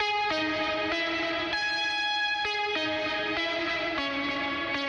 Synth Loop.wav